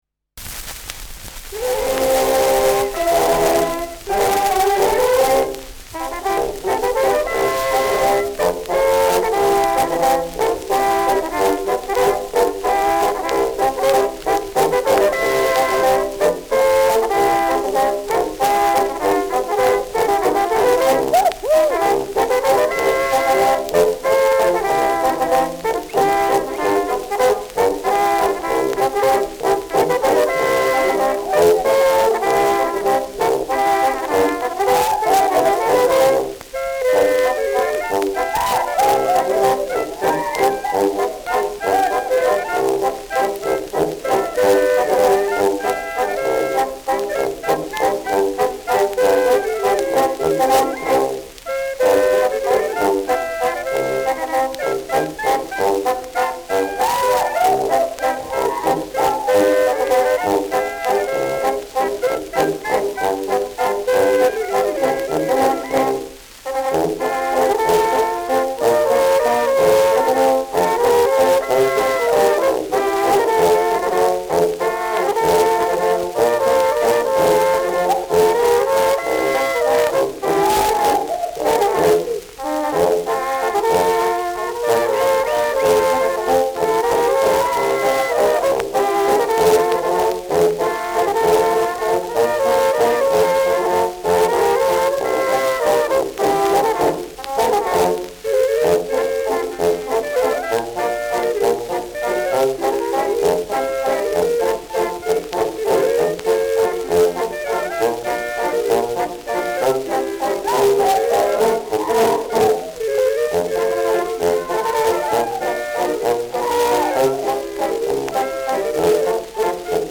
Schellackplatte
präsentes Rauschen : präsentes Knistern : gelegentliches „Schnarren“ : leiert : abgespielt
Dachauer Bauernkapelle (Interpretation)
Bauernkapelle Salzburger Alpinia (Interpretation)
Mit Juchzern.